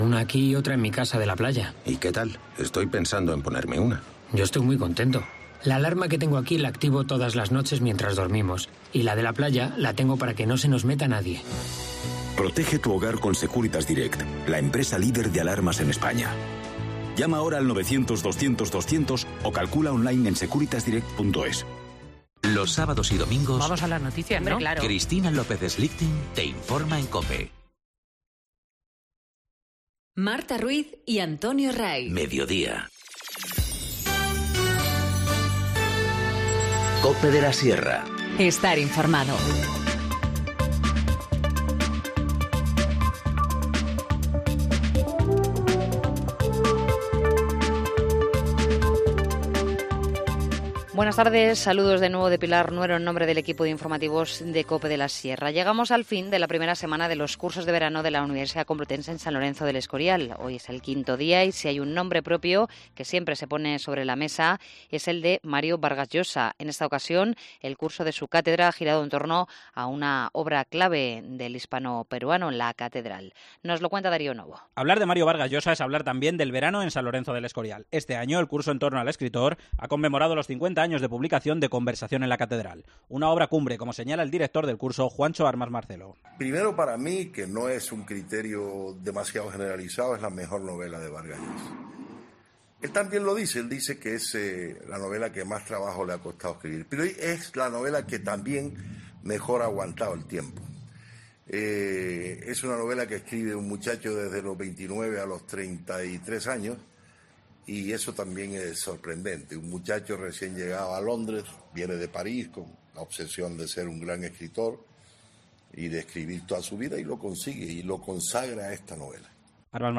Informativo Mediodía 5 julio 14:50h